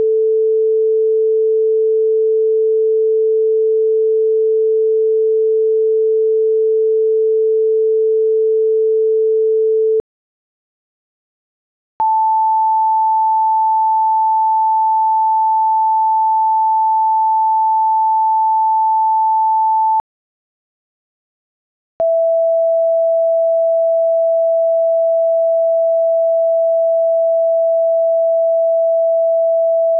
synthetic_multi_speaker.wav